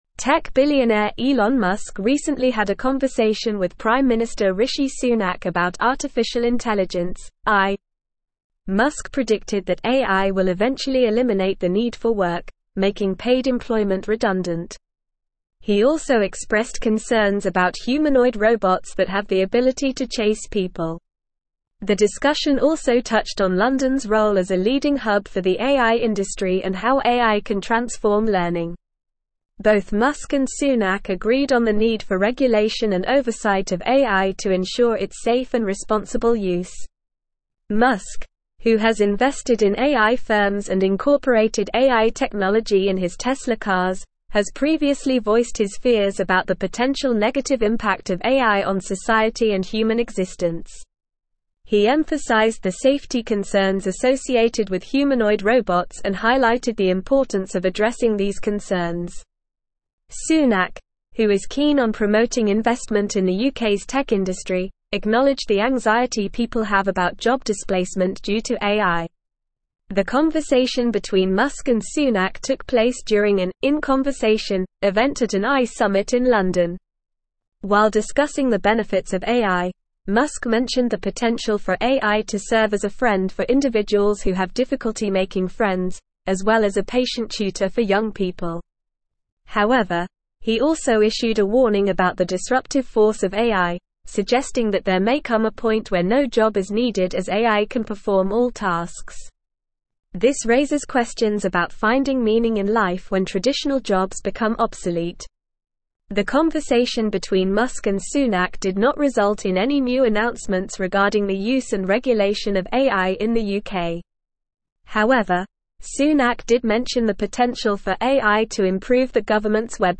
Normal
English-Newsroom-Advanced-NORMAL-Reading-Elon-Musk-and-Rishi-Sunak-discuss-AIs-impact.mp3